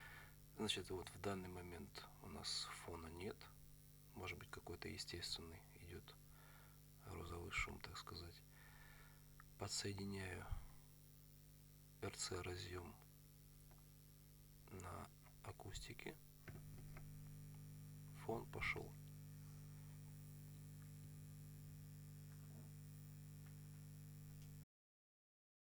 Последние полгода все чаще стал появляться фон в колонках. Как будто кто-то рубильник включил. Щёлк пошел фон.